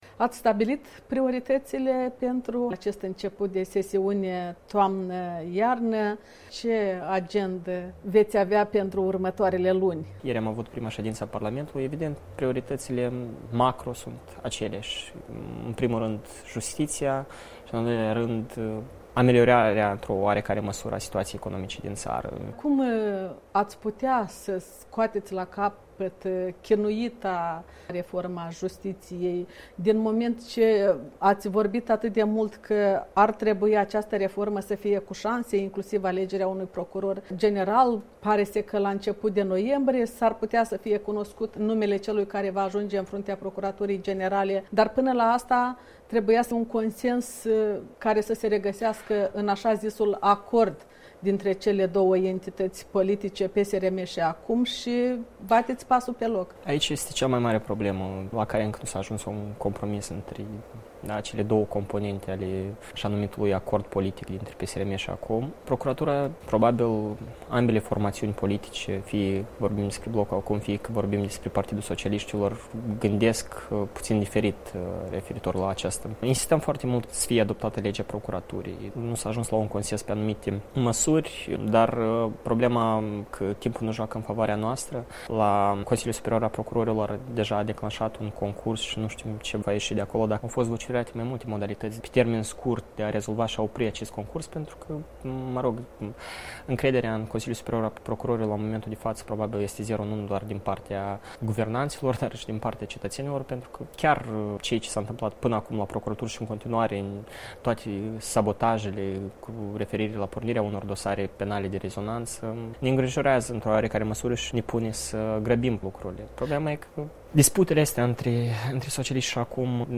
Interviu cu deputatul Blocului ACUM despre prioritățile Parlamentului la începutul sesiunii de toamnă- iarnă.